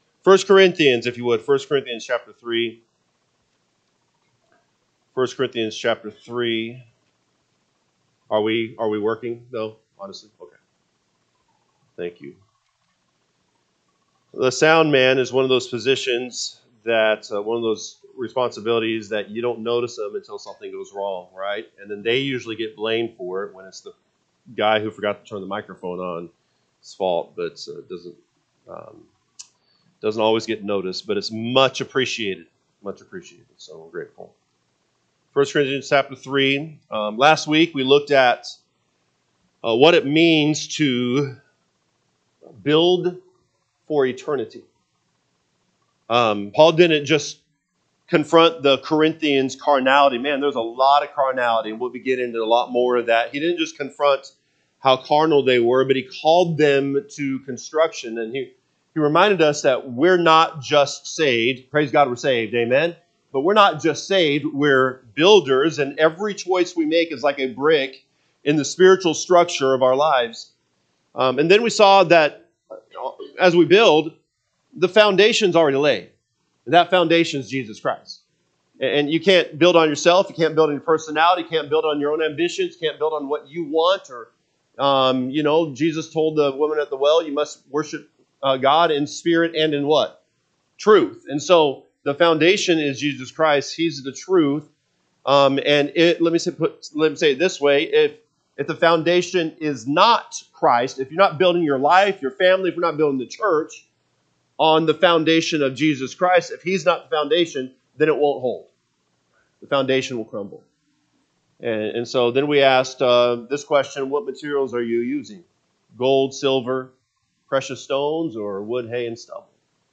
August 24, 2025 pm Service 1 Corinthians 3:16-23 (KJB) 16 Know ye not that ye are the temple of God, and that the Spirit of God dwelleth in you? 17 If any man defile the temple of God, hi…
Sunday PM Message